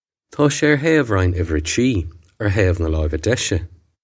Pronunciation for how to say
Taw shay air haive-rine ivvir a tree, air haive nah loy-ve desh-eh.
This is an approximate phonetic pronunciation of the phrase.